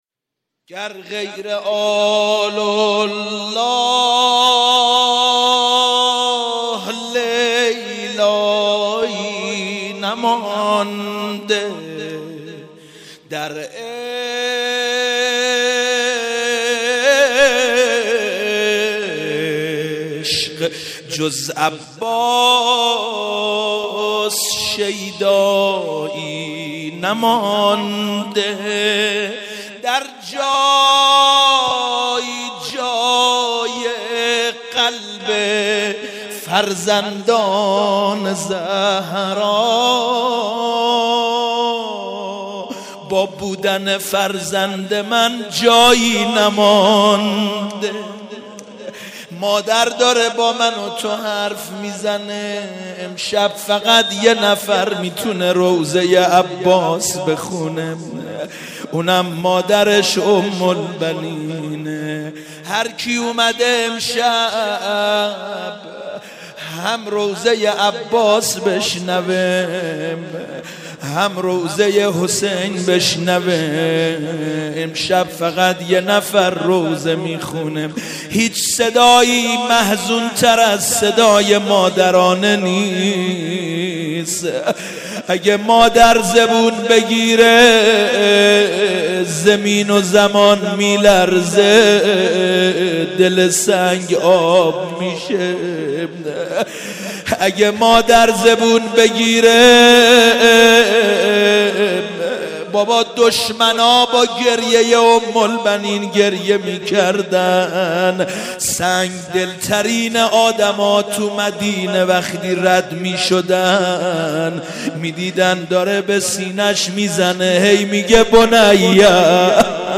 مناسبت : وفات حضرت ام‌البنین سلام‌الله‌علیها
قالب : روضه